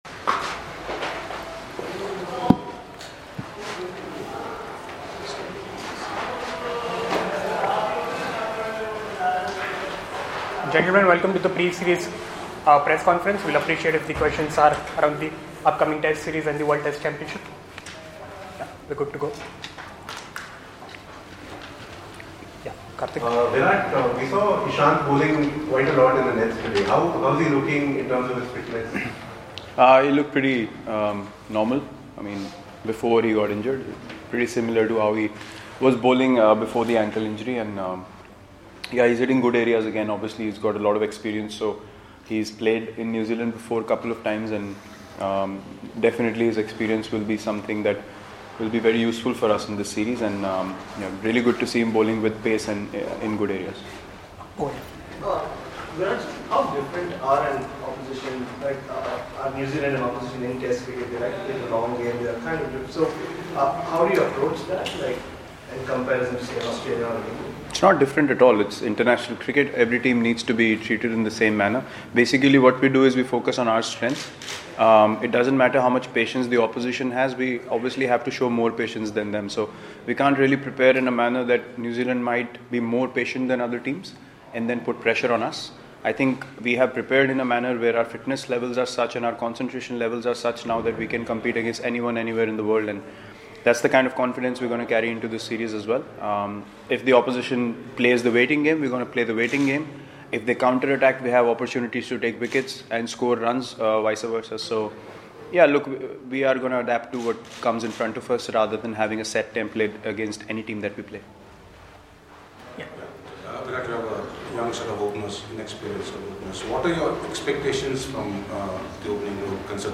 Virat Kohli spoke to the media ahead of the 1st Test against New Zealand in Wellington